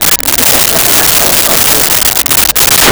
Crowd Laughing 02
Crowd Laughing 02.wav